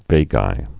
(vāgī, -jī)